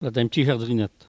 Maraîchin
Patois - archives
Catégorie Locution